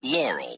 Audio S1. The original Laurel/Yanny audioclip. Note that many people do not exactly hear Yanny, but analogue forms such as Yari, Yelli, Yawee, etc.